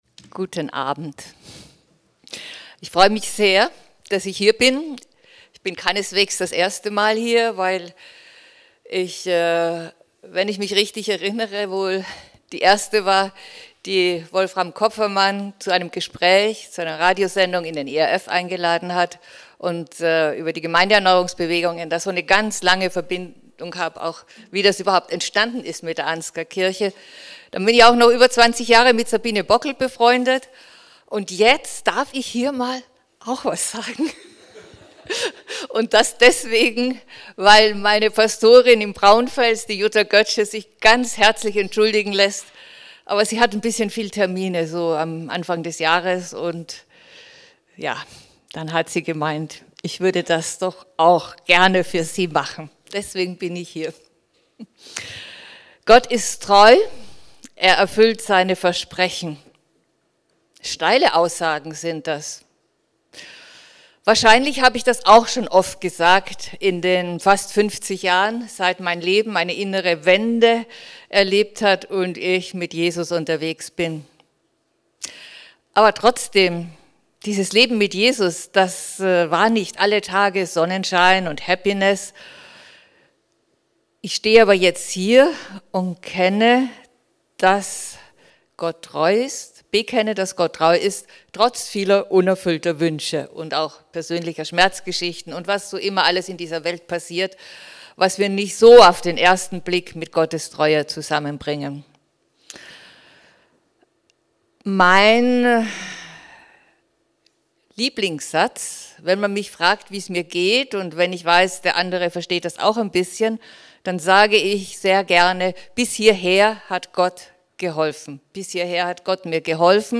Gott ist treu ~ Anskar Wetzlar // Predigt Podcast